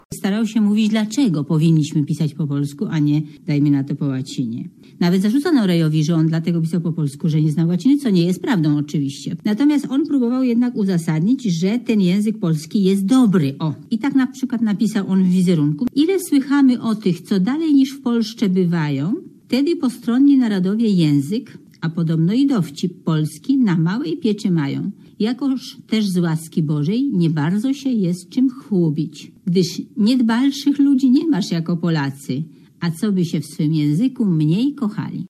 co przed laty na antenie Polskiego Radia podkreślała językoznawca profesor